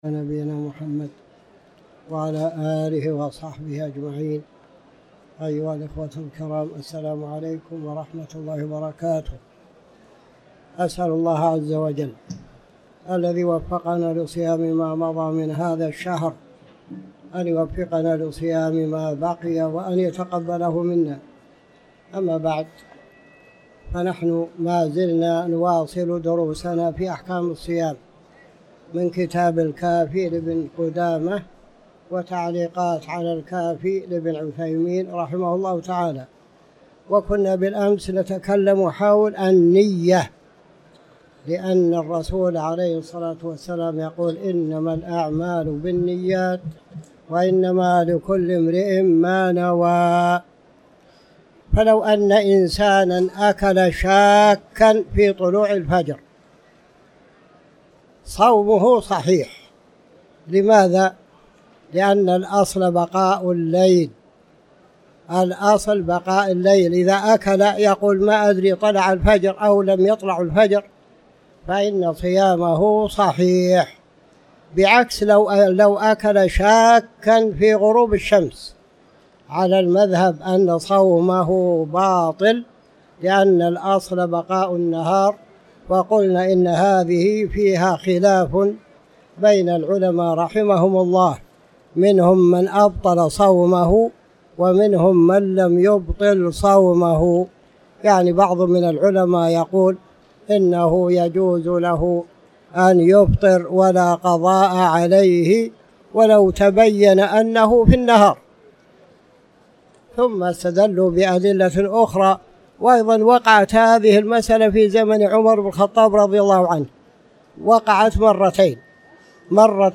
تاريخ النشر ٩ رمضان ١٤٤٠ هـ المكان: المسجد الحرام الشيخ